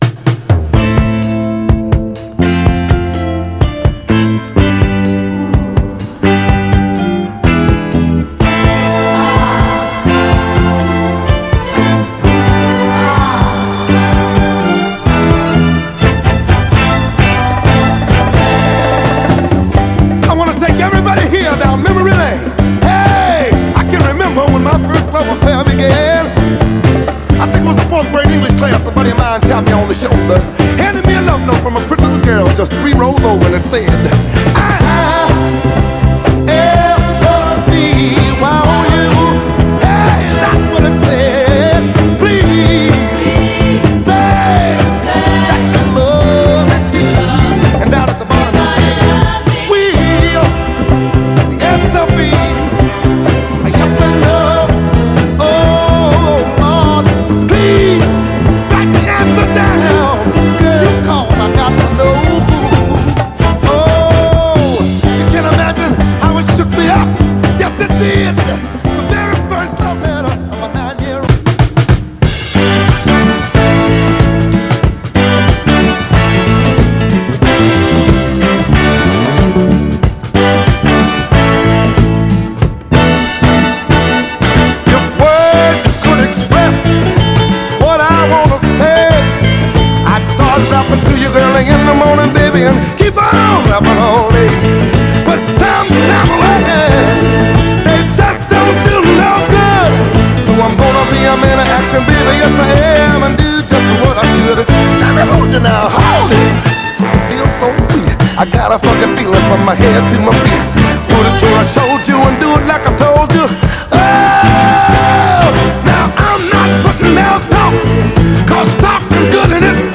ハープシコードが活躍する、熱き情熱のフィリー・ダンサー
タフでクロスオーヴァーな軽快ノーザン・ファンク・ダンサー
※試聴音源は実際にお送りする商品から録音したものです※